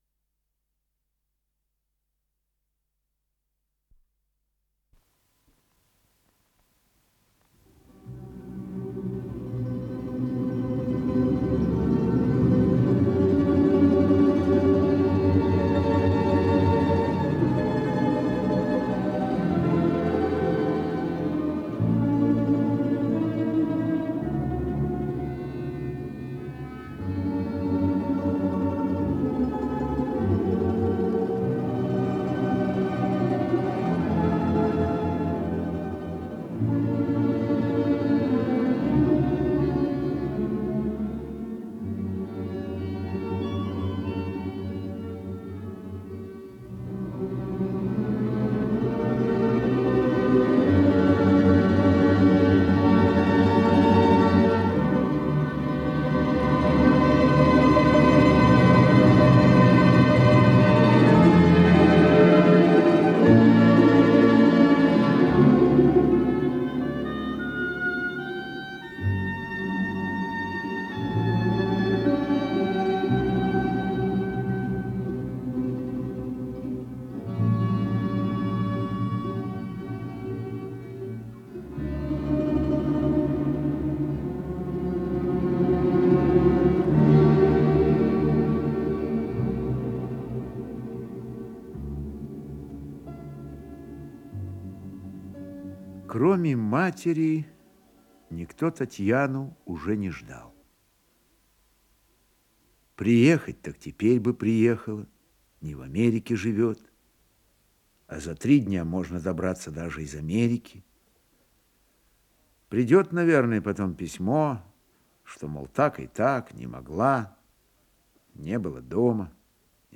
Сценическая композиция, повесть, часть 2-я